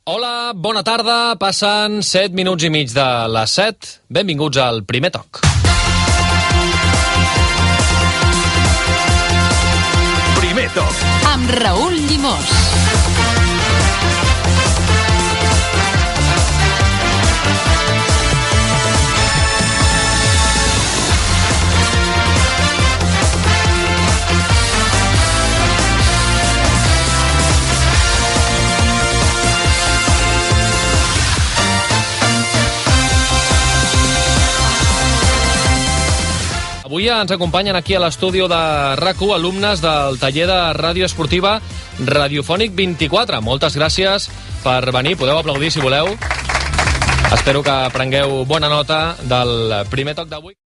Hora, benvinguda, careta del programa i salutació a l'alumnat de Radiofònics present a l'estudi
Esportiu